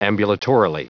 Prononciation du mot ambulatorily en anglais (fichier audio)
ambulatorily.wav